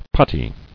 [put·ty]